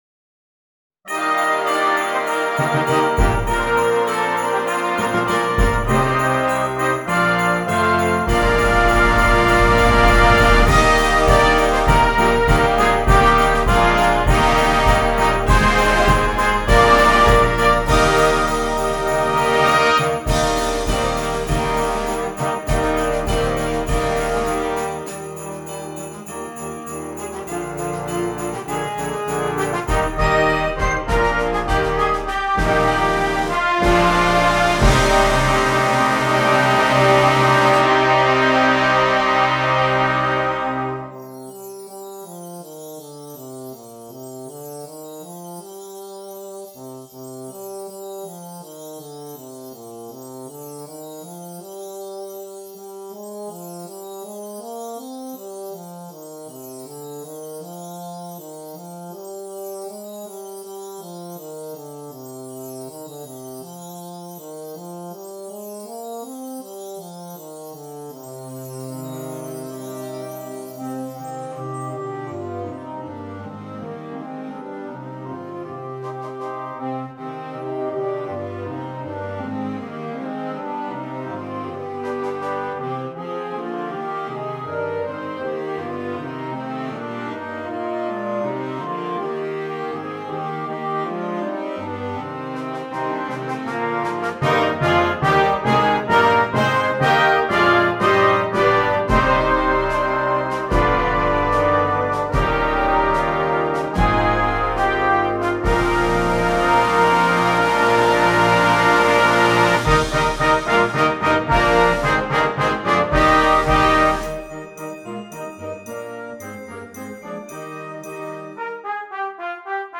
Concert Band
Traditional